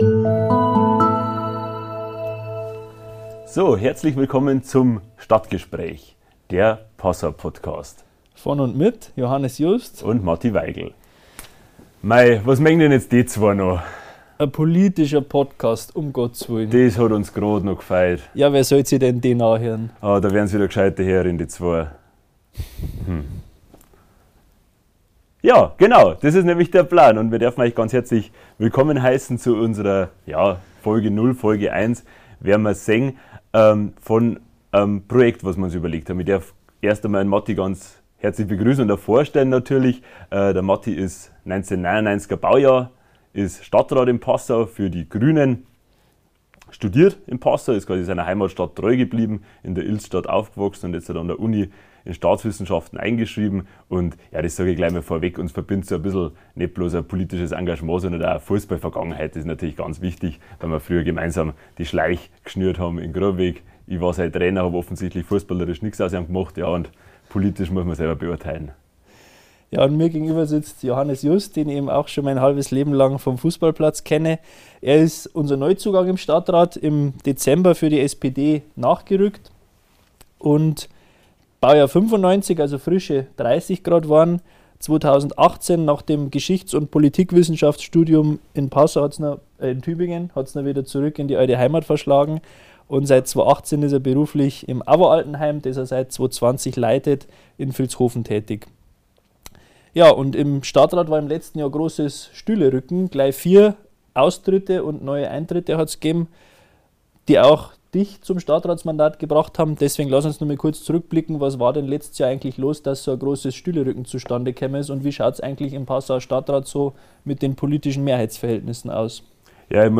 Zwei junge Passauer Stadträte, Jugendfreunde, der eine bei den Grünen, der andere bei der SPD, unterhalten sich zum Auftakt über Brennpunkte der Lokalpolitik: Hochwasserschutz, Fünferlsteg und Sport.